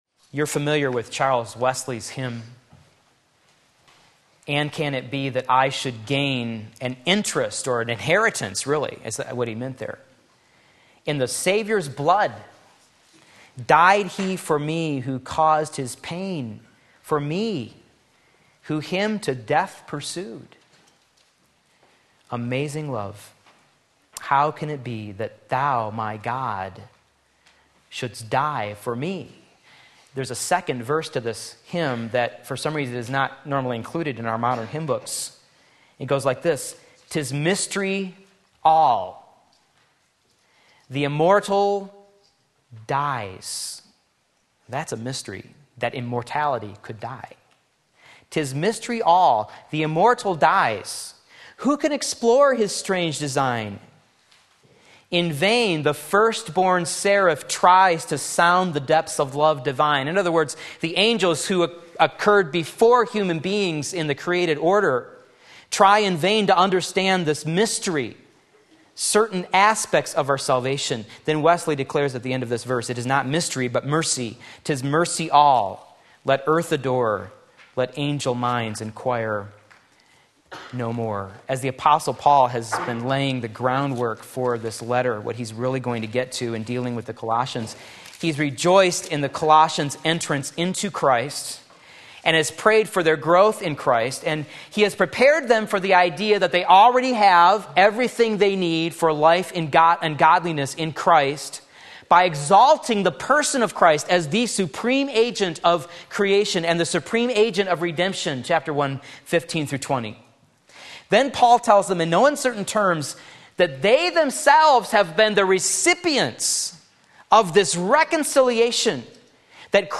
Sermon Link
2:3 Sunday Morning Service